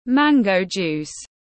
Nước ép xoài tiếng anh gọi là mango juice, phiên âm tiếng anh đọc là /ˈmæŋ.ɡəʊ ˌdʒuːs/
Mango juice /ˈmæŋ.ɡəʊ ˌdʒuːs/